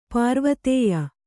♪ pārvatēya